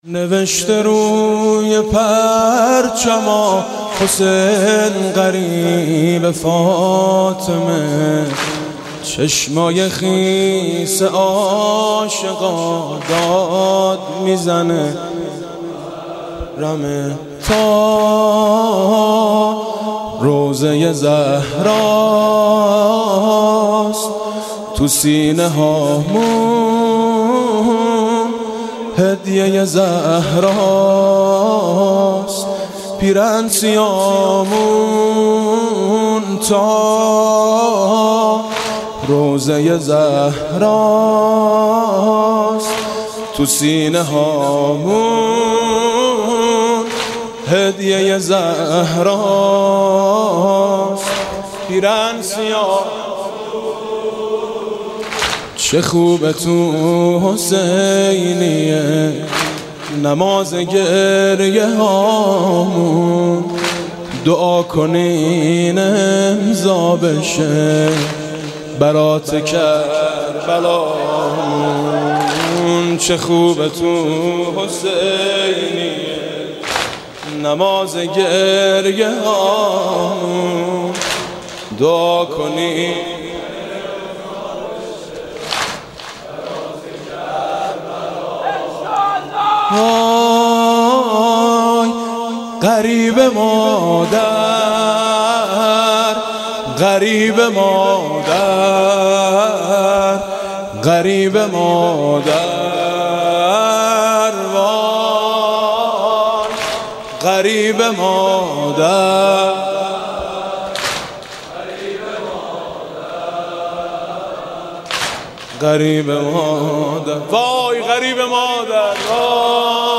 مداحی محرم
مداحی شب اول محرم